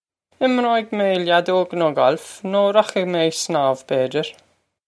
Pronunciation for how to say
Im-roh-ig may lyad-ohg noh golf, noh rokh-ig may egg sn-awv, bay-dyir.
This is an approximate phonetic pronunciation of the phrase.
This comes straight from our Bitesize Irish online course of Bitesize lessons.